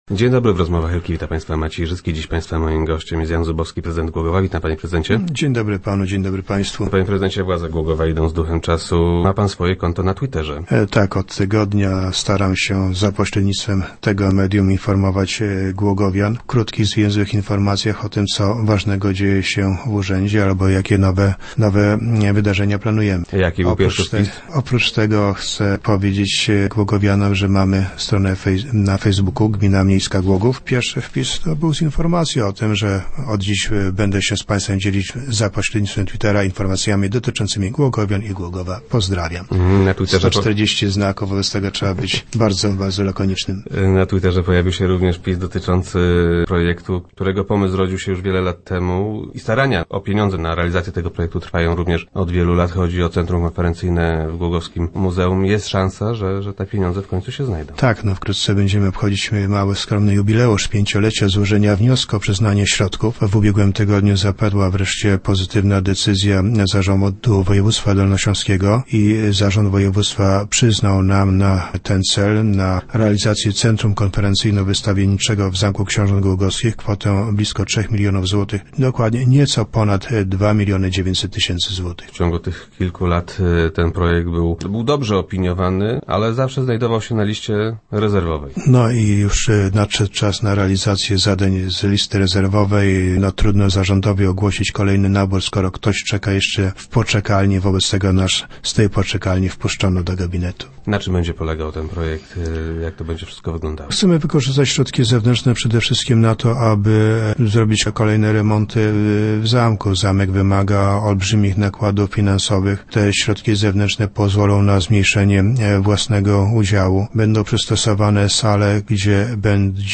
0511_zubowski_nowy.jpgSą szanse na to, że pomysł utworzenia w głogowskim zamku nowoczesnego centrum wystawienniczo-konferencyjnego, doczeka się realizacji. - Zarząd województwa przyznał nam na ten cel blisko trzy miliony złotych - poinformował prezydent Jan Zubowski, który był gościem Rozmów Elki.